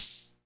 TR-55 OPEN0H.wav